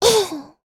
Taily-Vox_Landing_jp_c.wav